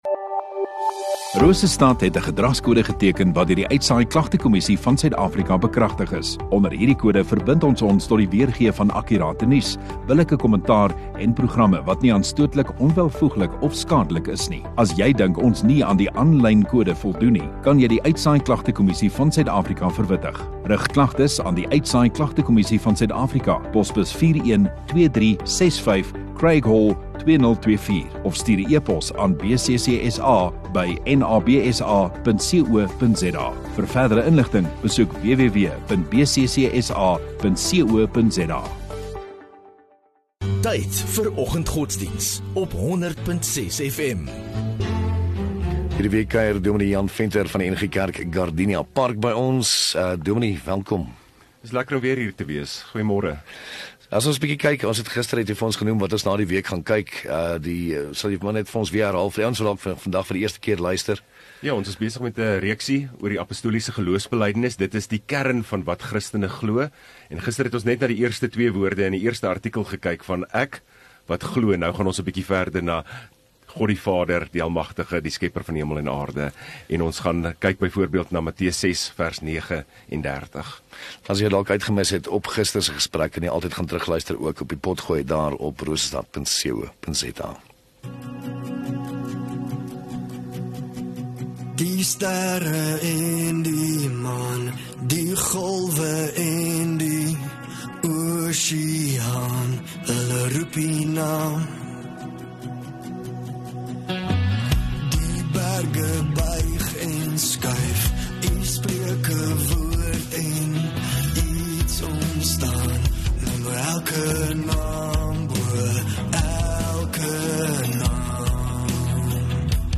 31 Oct Dinsdag Oggenddiens